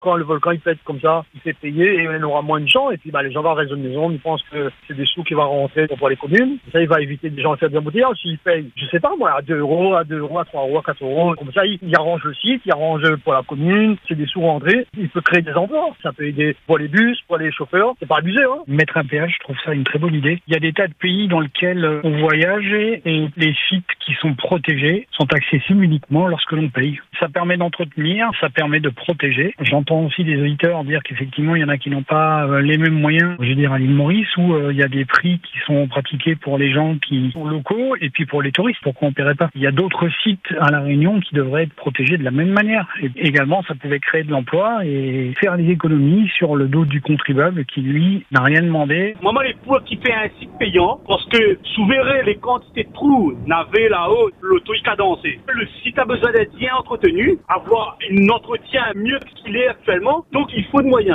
C’est la proposition avancée par un auditeur sur l’antenne.